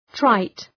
{traıt}